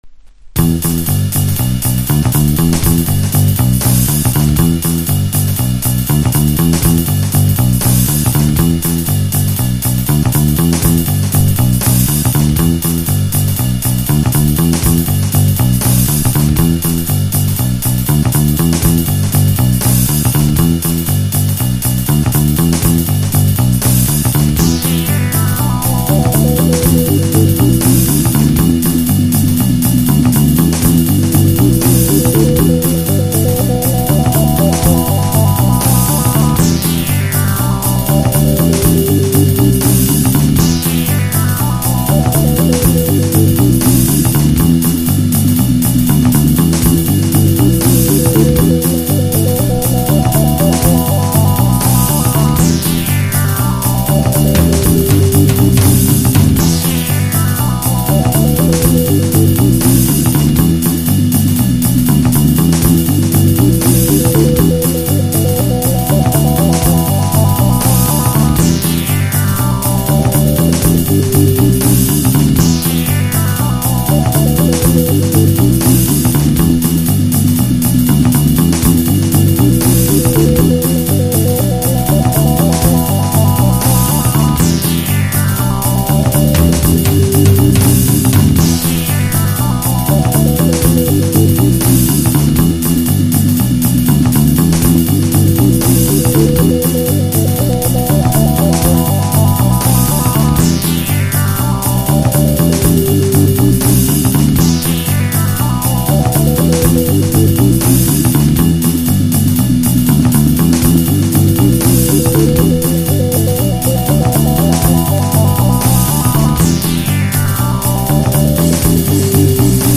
HEADZ / ELECTRONICA / CHILOUT